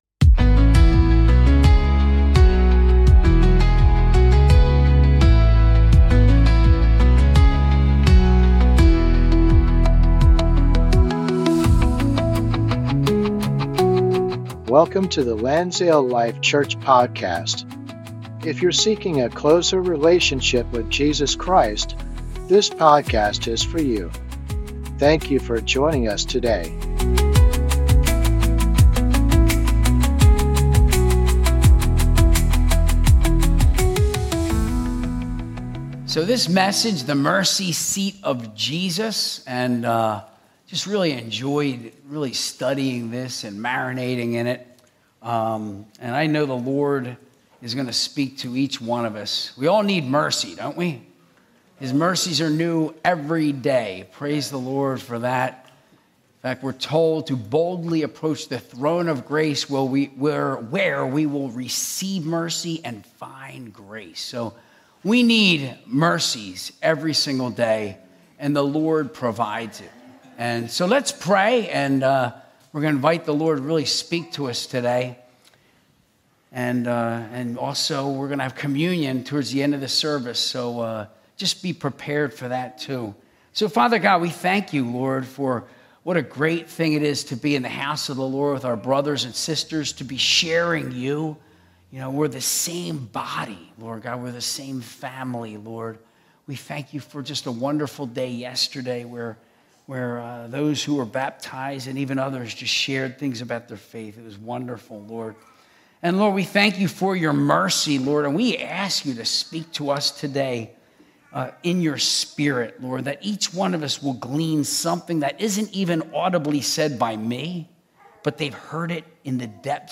Sunday Service - 2025-10-05